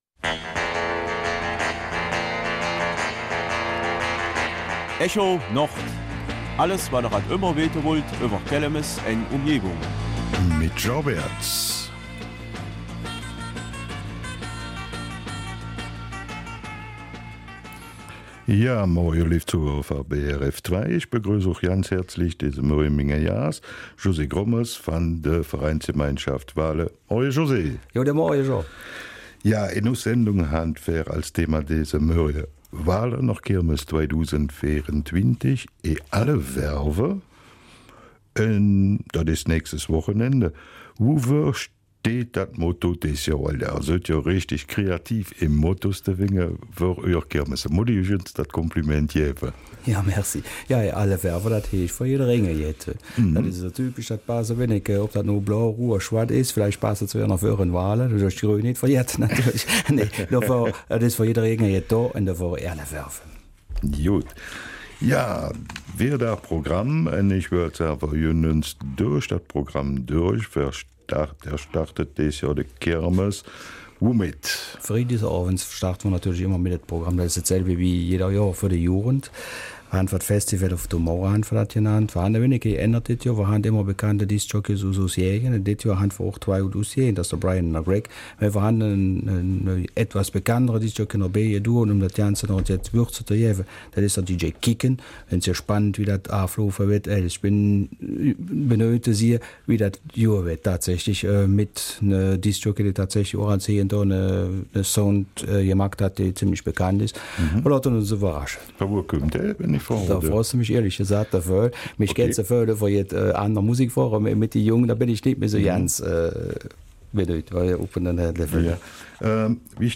Kelmiser Mundart: Walhorner Kirmes 2024 in allen Farben!